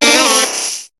Cri de Mystherbe dans Pokémon HOME.